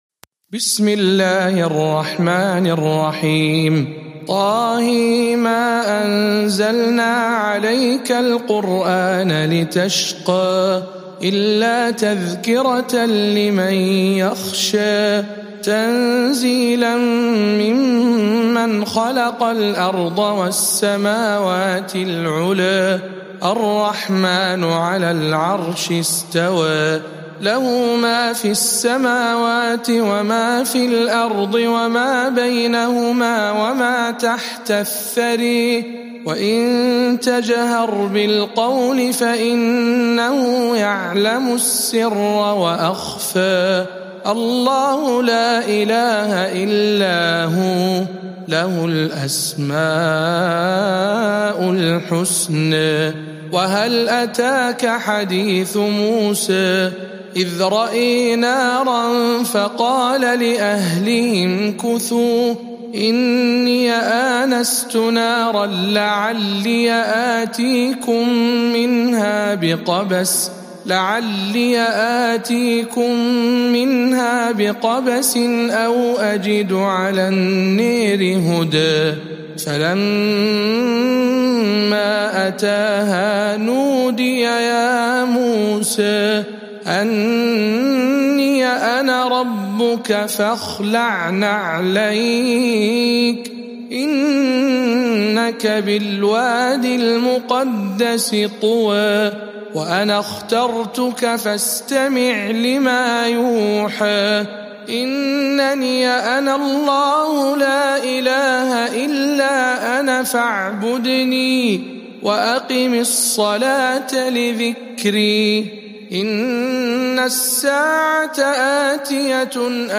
سورة طه برواية الدوري عن أبي عمرو